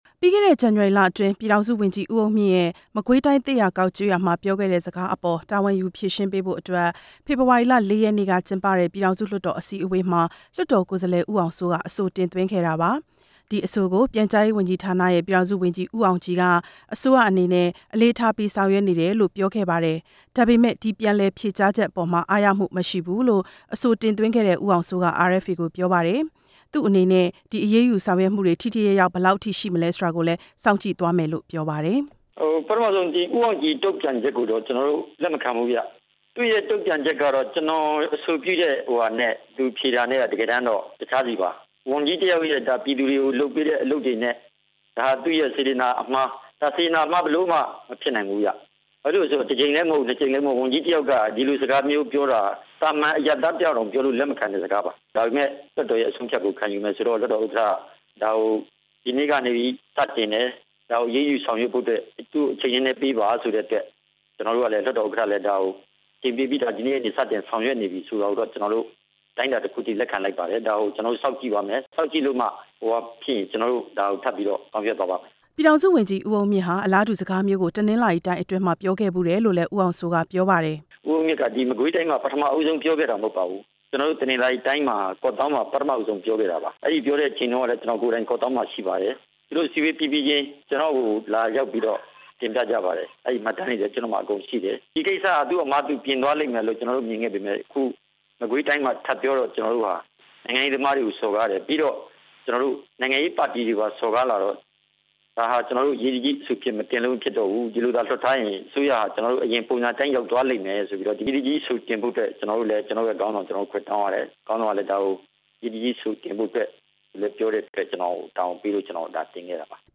ပြည်သူလွှတ်တော် ကိုယ်စားလှယ် ဦးဖေသန်း ပြောပြသွားတာပါ၊ ဝန်ကြီး ဦးအုန်းမြင့်ရဲ့ စကားတွေထဲမှာ နိုင်ငံရေးသမားတွေကို စော်ကားမှုတွေ ပါဝင်တဲ့အတွက် အရေးယူပေးဖို့ဆိုပြီး လွှတ်တော်အစည်းအဝေးမှာ အရေးကြီးအဆို တင်သွင်းခဲ့တာဖြစ်ပါတယ်၊